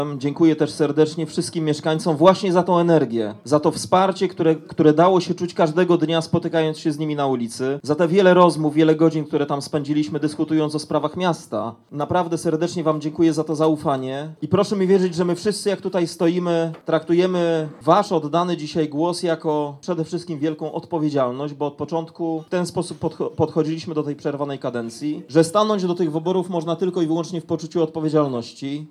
– mówił podczas ogłaszania nieoficjalnych jeszcze wyników w swoim sztabie Rafał Zając.
zając przemówienie news.mp3